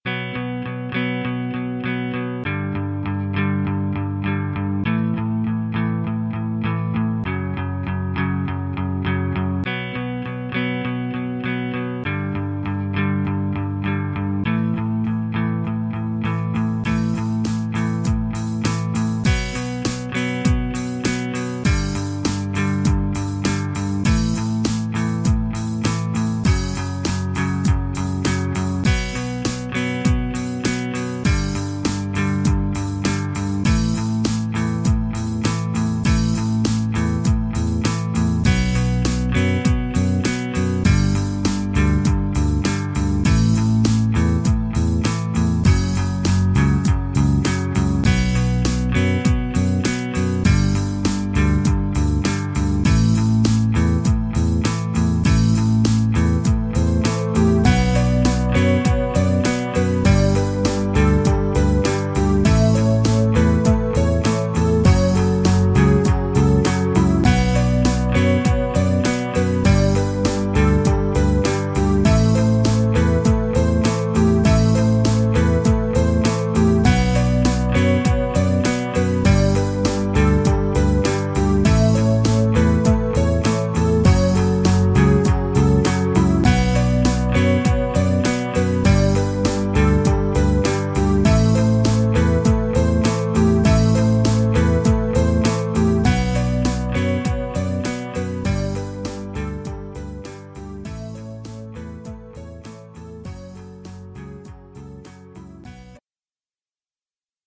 Here is a small composition I did recently while experimenting with separating instruments in a recording: